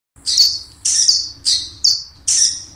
Bat Chirping Type 2 Bouton sonore